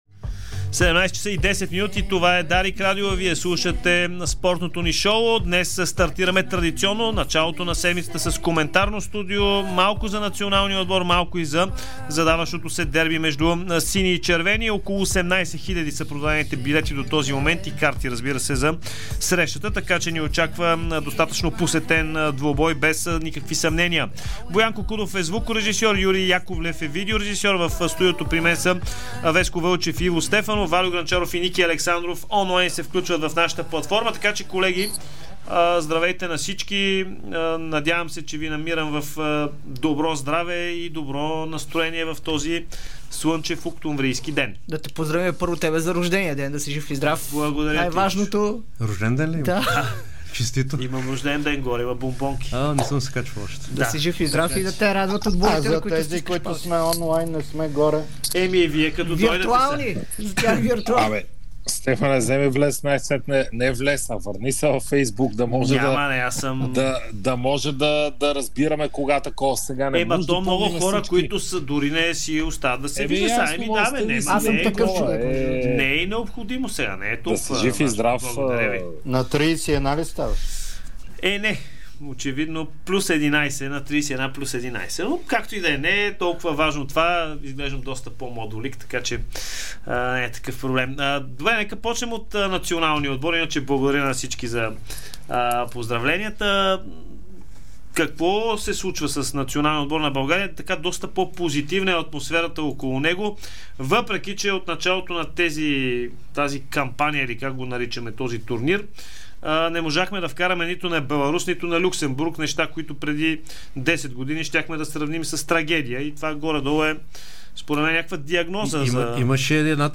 Коментарно студио: След Люксембург с поглед към Северна Ирландия и преди дербито